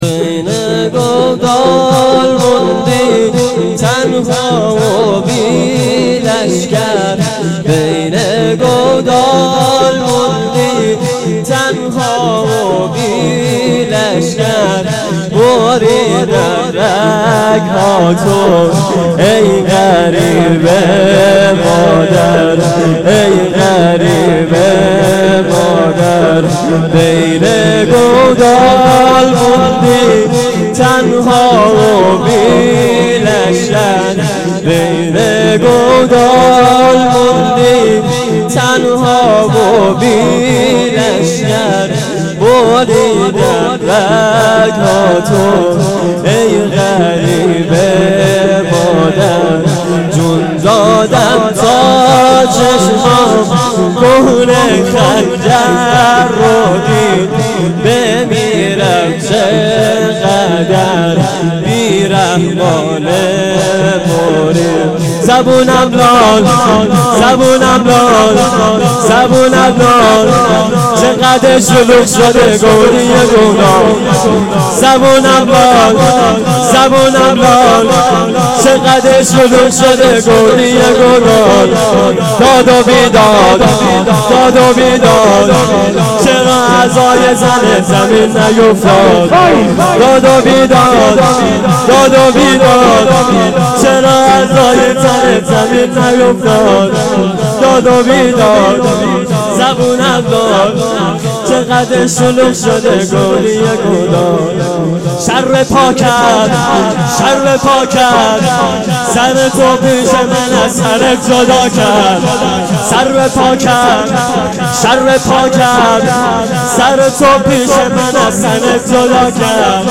شور شب یازدهم محرم 98